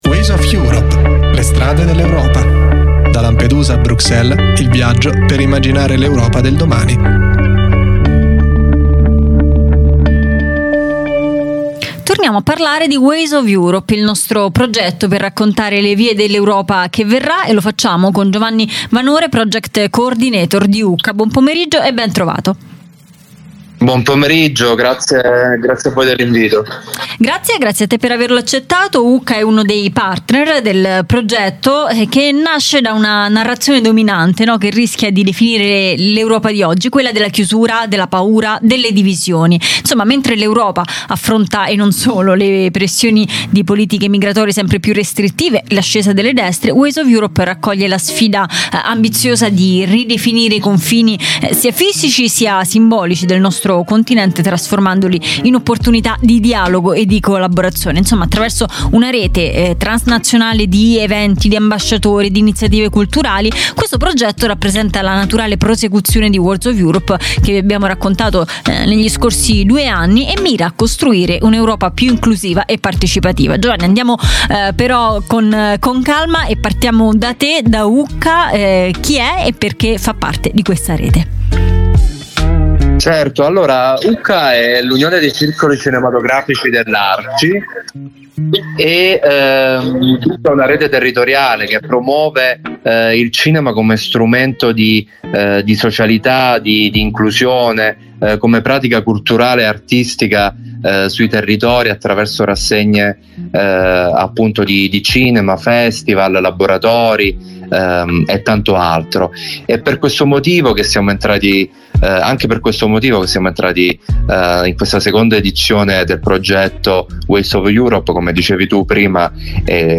Cinema as a tool for change: interview